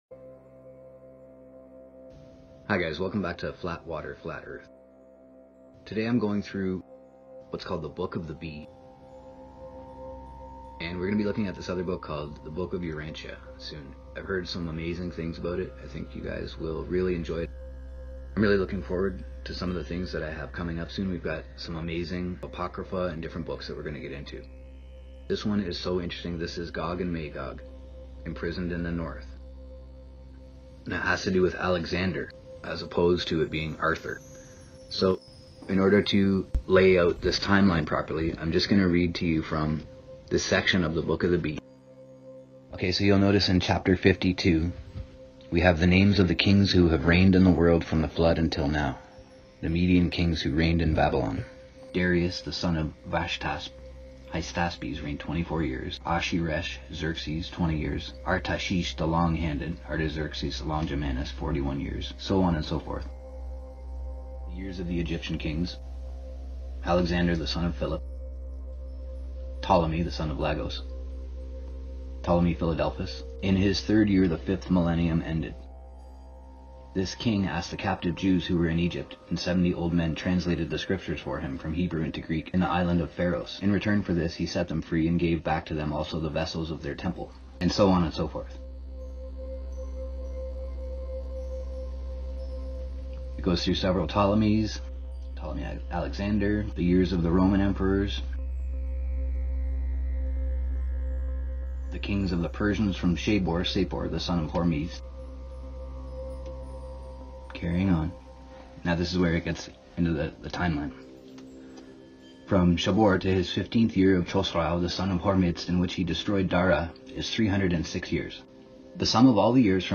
Reading from The Book Of The Bee Chapters 52-54.